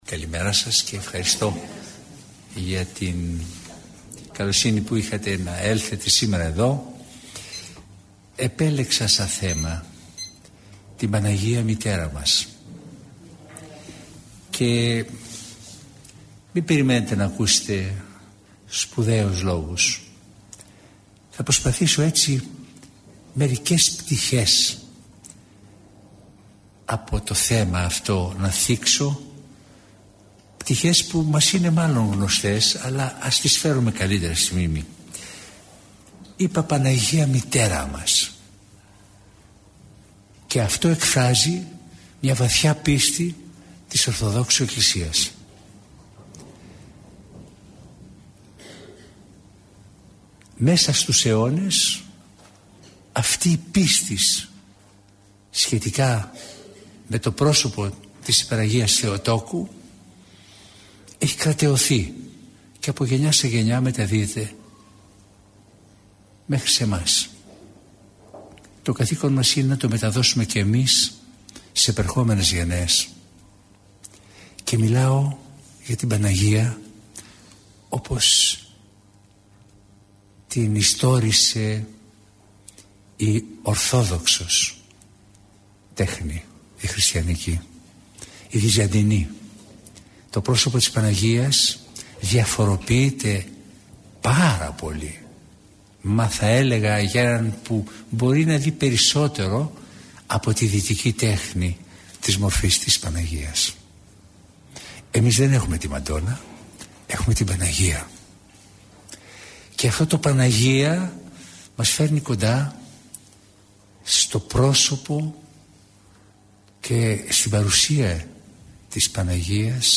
Η Παναγία μητέρα μας – ηχογραφημένη ομιλία
Η ομιλία αυτή “δόθηκε” στα πλαίσια του σεμιναρίου Ορθοδόξου πίστεως – του σεμιναρίου οικοδομής στην Ορθοδοξία, στις 5 Δεκεμβρίου του 2010. Το σεμινάριο αυτό διοργανώνεται στο πνευματικό κέντρο του Ιερού Ναού της Αγ. Παρασκευής (οδός Αποστόλου Παύλου 10), του ομωνύμου Δήμου της Αττικής.